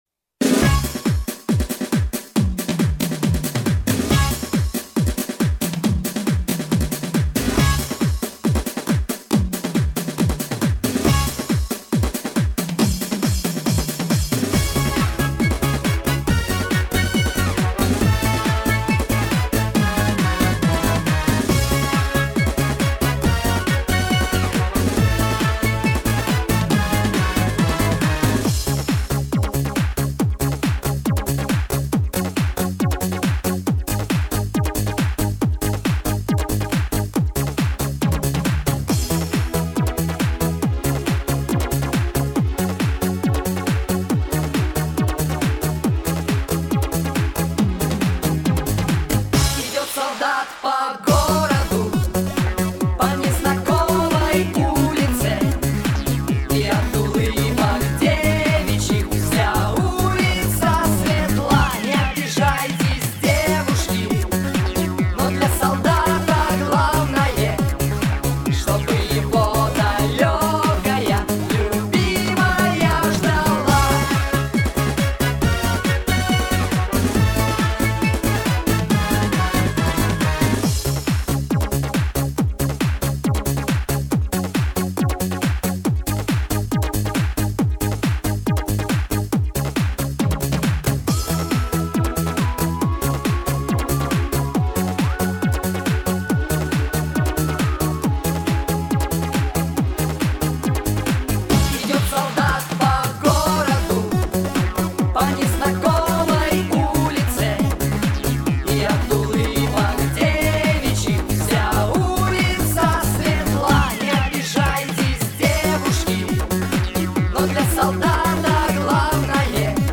песня на 23 февраля.